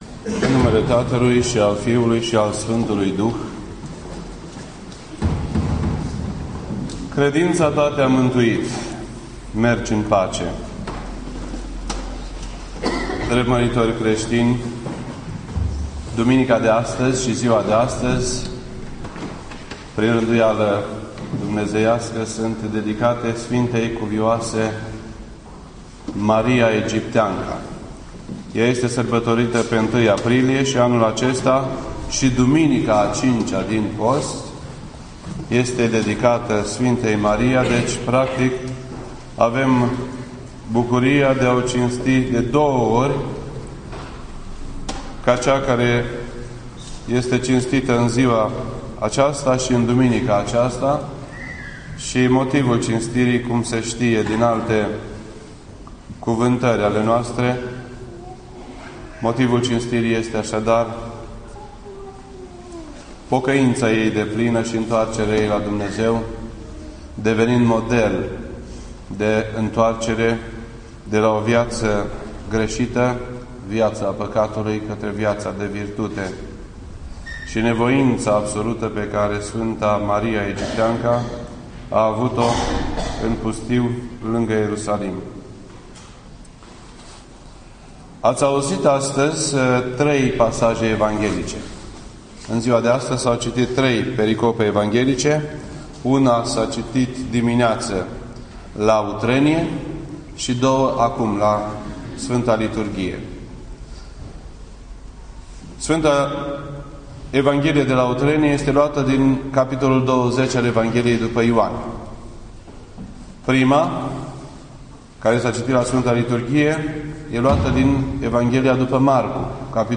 This entry was posted on Sunday, April 1st, 2012 at 8:46 PM and is filed under Predici ortodoxe in format audio.